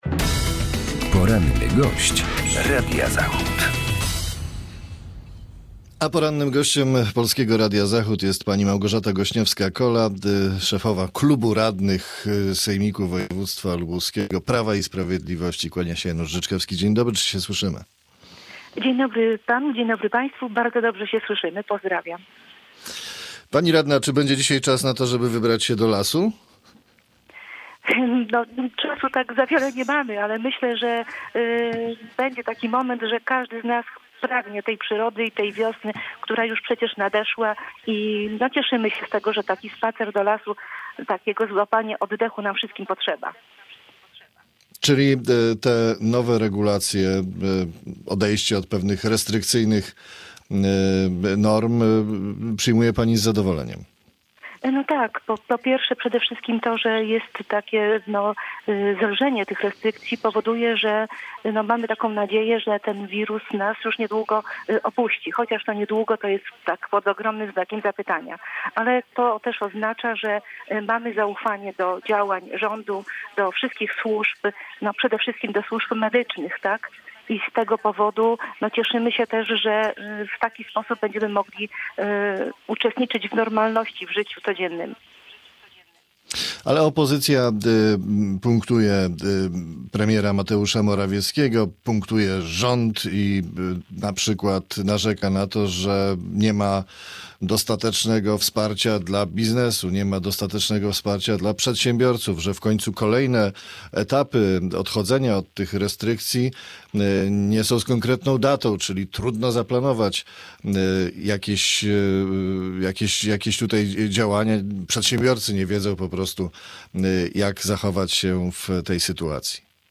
Z radną sejmiku lubuskiego (PiS) rozmawia